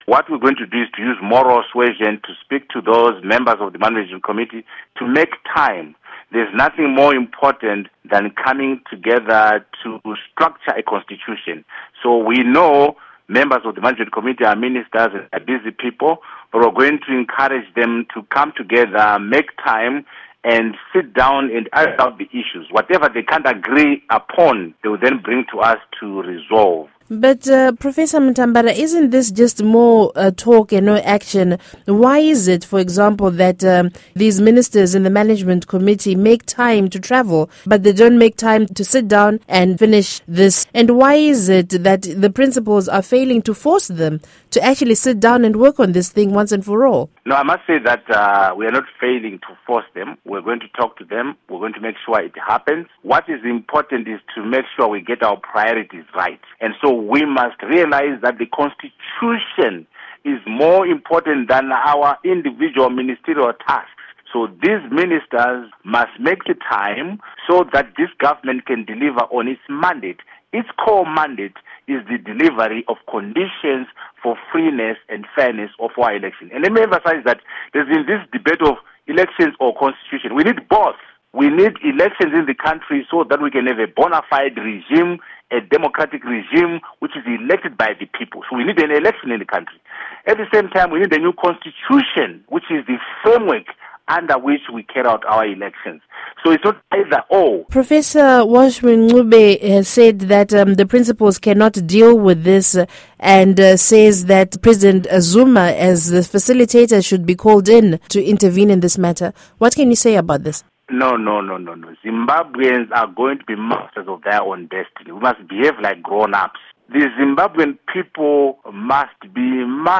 Interview with Arthur Mutambara